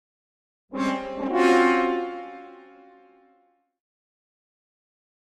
Horn Fanfare Victory Signal - Derisive Version 1